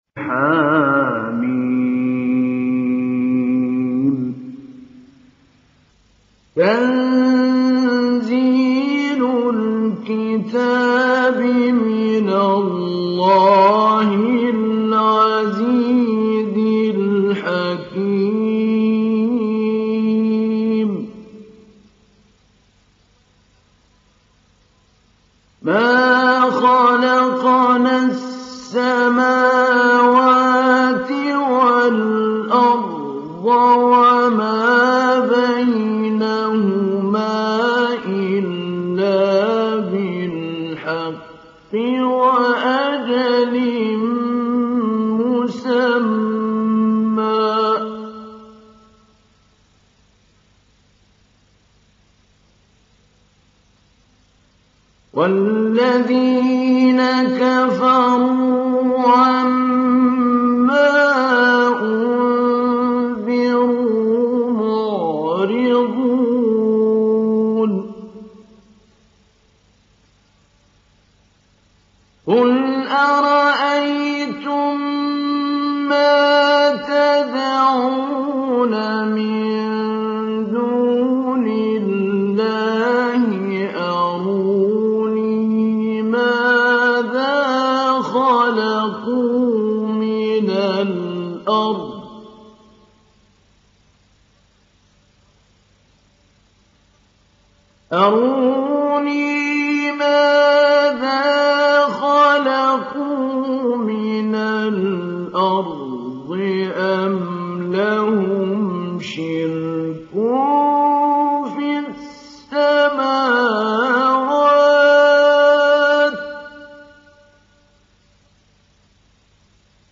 تحميل سورة الأحقاف mp3 بصوت محمود علي البنا مجود برواية حفص عن عاصم, تحميل استماع القرآن الكريم على الجوال mp3 كاملا بروابط مباشرة وسريعة
تحميل سورة الأحقاف محمود علي البنا مجود